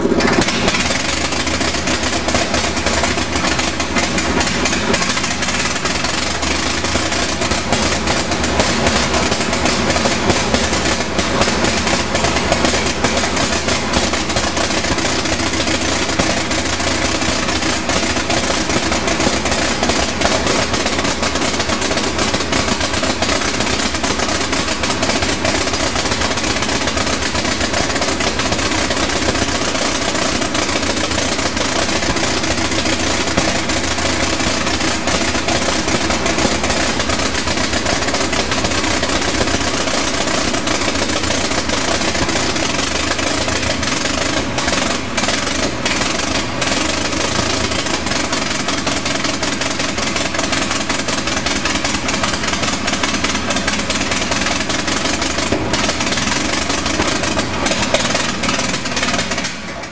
1903 Engine3.wav